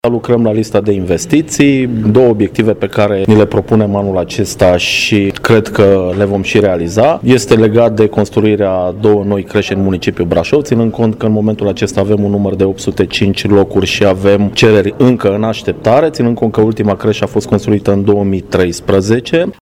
Viceprimarul Costel Mihai a anunțat cu prilejul unei conferințe de presă că la Brașov se vor construi două noi creșe.
Mihai Costel, viceprimar Brașov: